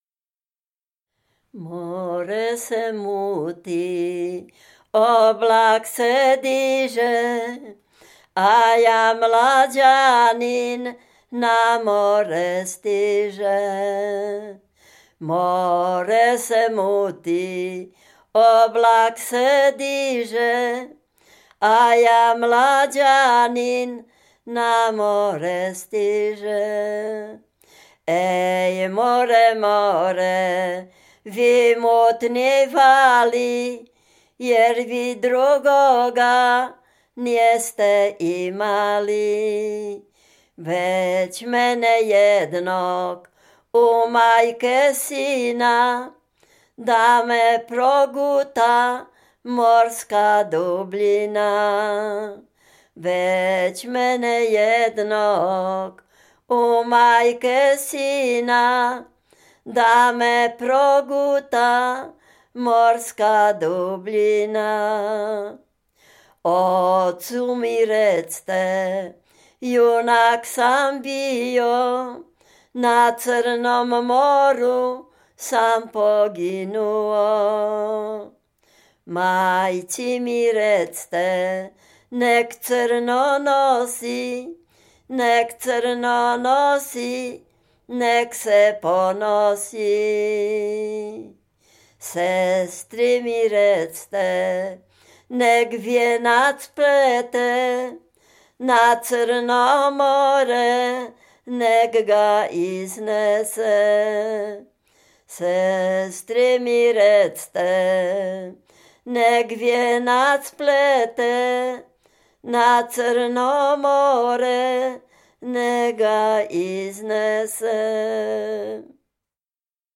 Dolny Śląsk, powiat bolesławiecki, gmina Nowogrodziec, wieś Zebrzydowa
rekruckie wojenkowe